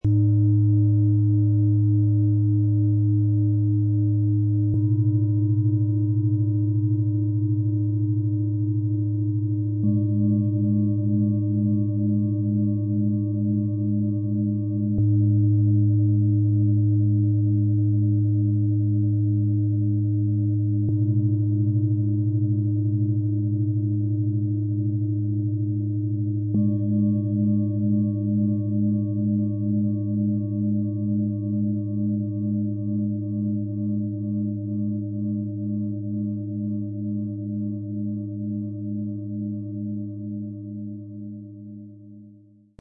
Lass los, was war, und spüre, was in Dir steckt - Set aus 3 Planetenschalen für Klangmassage und Klangmeditationen, Ø 16,2 -24,1 cm, 2,81 kg
Herzöffnung, Wandlung und innere Stärke vereinen sich in diesem harmonisch abgestimmten Dreiklang.
Hinweis: Alle drei Schalen sind hervorragend aufeinander abgestimmt und entfalten gemeinsam eine kraftvolle Schwingung.
Dank unseres Sound-Player - Jetzt reinhörens können Sie den echten Klang dieser drei besonderen Planetenschalen direkt anhören und ihre harmonische Schwingung auf sich wirken lassen.
Im Lieferumfang ist ein passender Schlägel enthalten, mit dem alle drei Schalen sanft und harmonisch erklingen.
Tiefster Ton: Neptun, Eros
Bengalen-Schale, glänzend, 16,2 cm Durchmesser, 8,1 cm Höhe